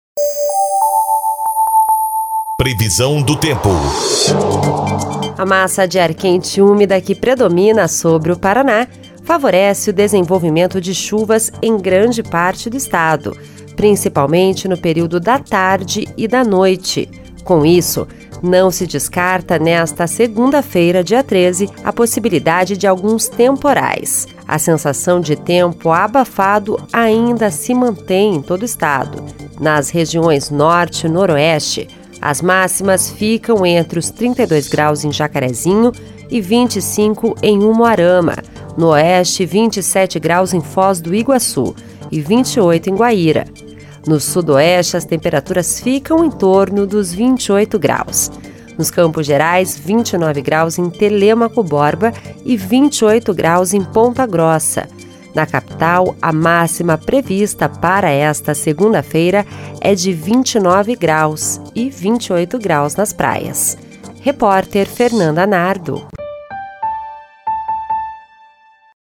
Previsão do Tempo (12/12)